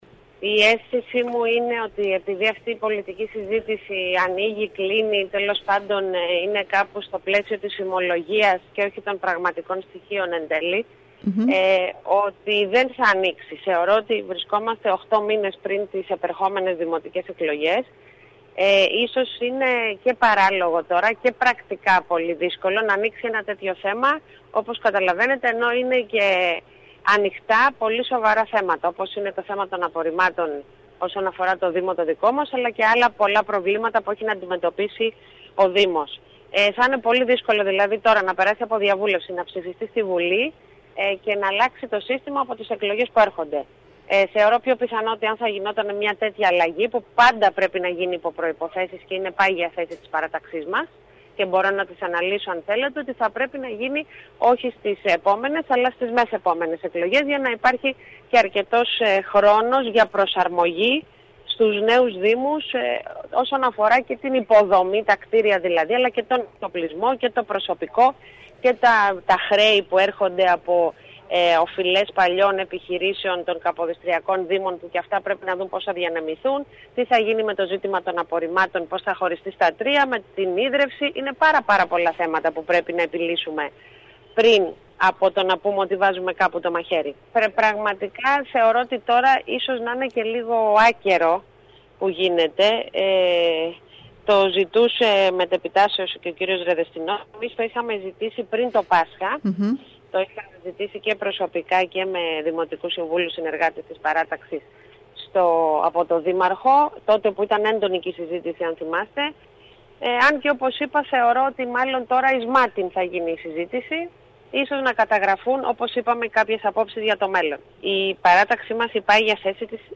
Μιλώντας στην ΕΡΤ Κέρκυρας η επικεφαλής της παράταξης “Κερκυραίων Δήμος” Μερόπη Υδραίου επεσήμανε ότι στην παρούσα φάση η όποια αλλαγή στο χωροταξικό θα είναι καταστροφική. Αυτό που χρειάζεται, όπως είπε, είναι πόροι και σοβαρός σχεδιασμός.